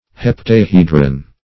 Search Result for " heptahedron" : The Collaborative International Dictionary of English v.0.48: Heptahedron \Hep`ta*he"dron\, n. [Hepta- + Gr.